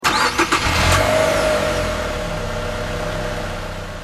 Home gmod sound vehicles tdmcars mx5
enginestart.mp3